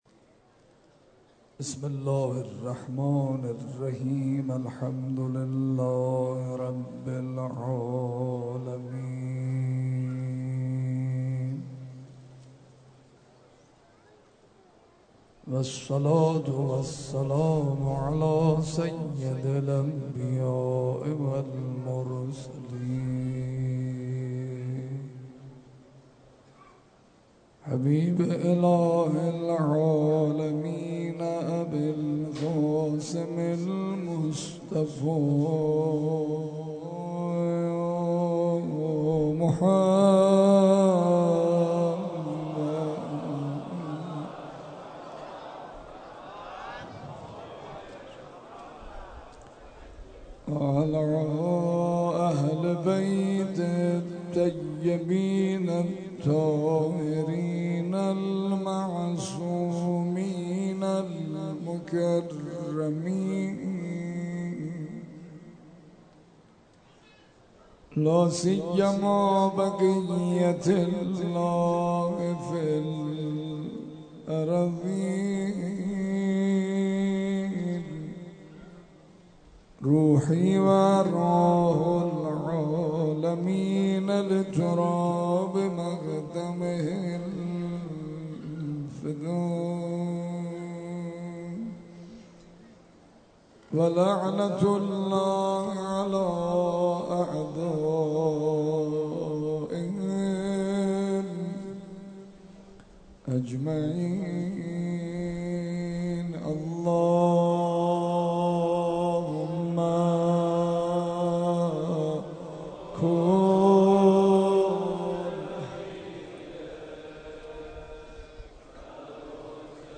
مناجات و روضه شب عاشورا